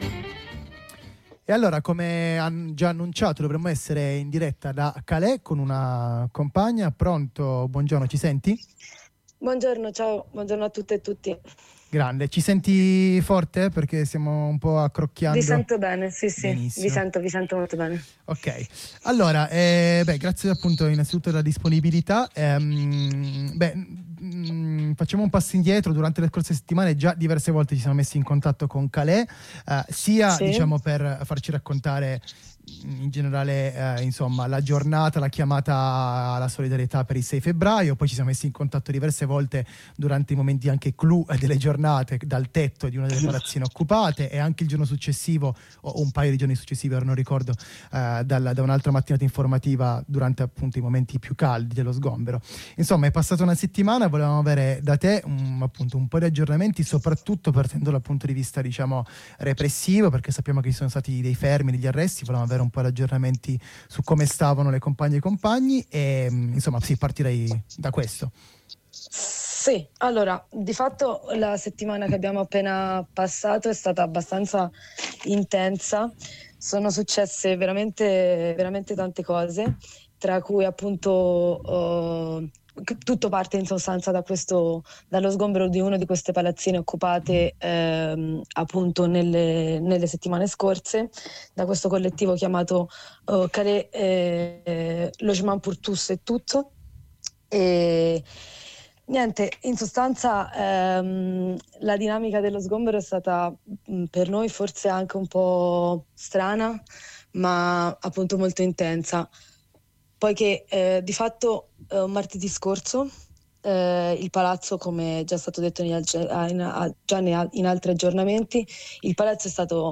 Di tutto questo, e degli aggiornamenti su* compagn* colpiti da repressione, ne parla una compagna ai nostri microfoni: Per info aggiuntive e aggiornamenti Tweets by CalaisLogement